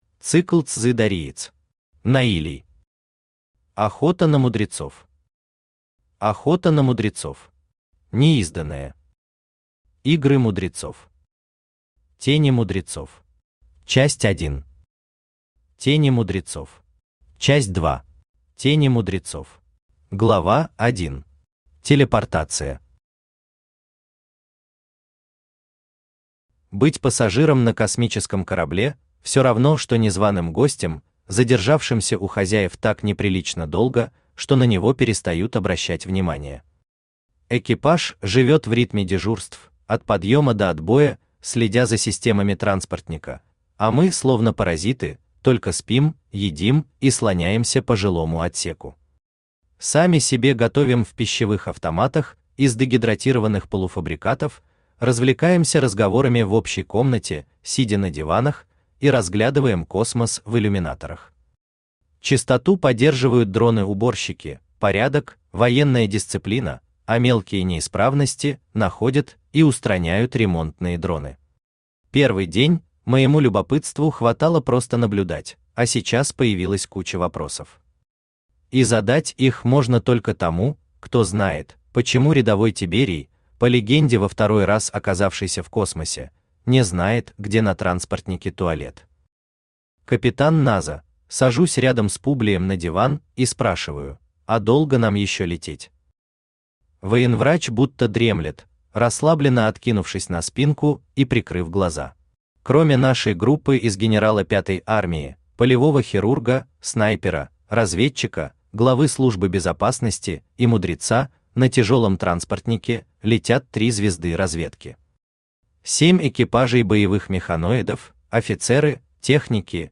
Аудиокнига Тени мудрецов. Часть 1 | Библиотека аудиокниг
Часть 1 Автор Дэлия Мор Читает аудиокнигу Авточтец ЛитРес.